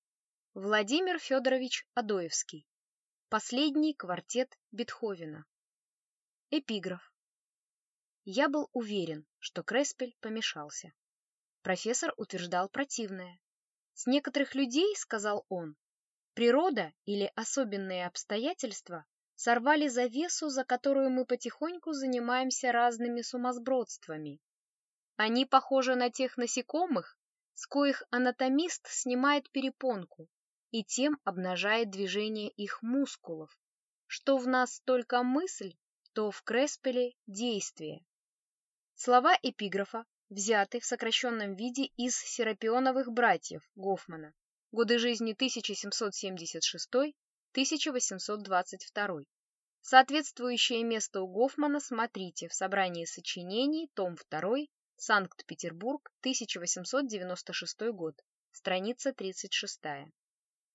Аудиокнига Последний квартет Бетховена | Библиотека аудиокниг